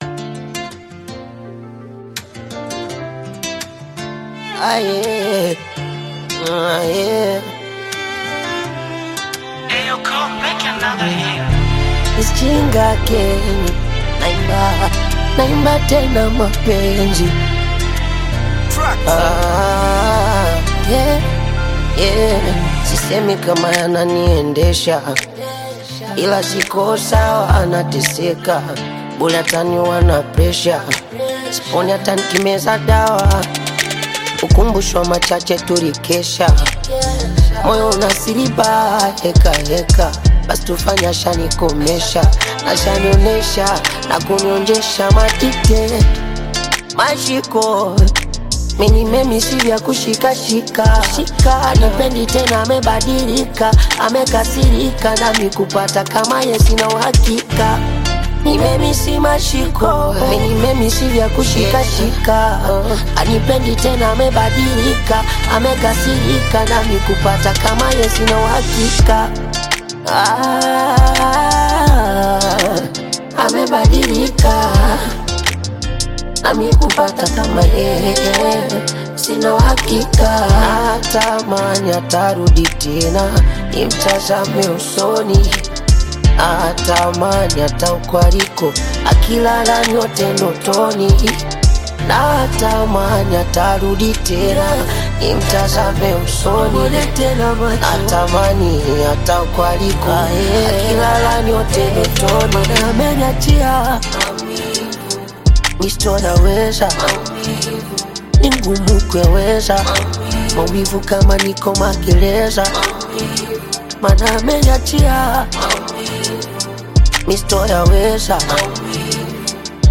deeply emotional new single
smooth melodies
expressive vocal delivery